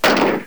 gun2.wav